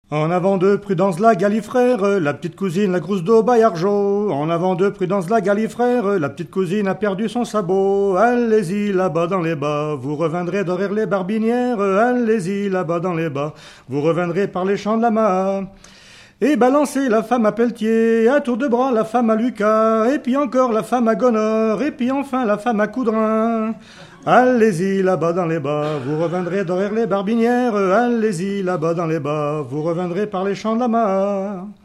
danse : branle : avant-deux ;
Pièce musicale inédite